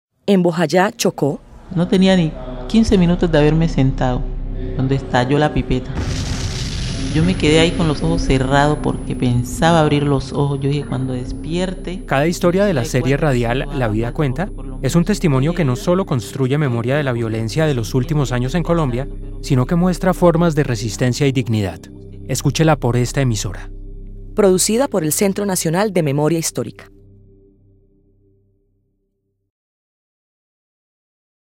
Promoción Bojayá: Rosa de las nieves.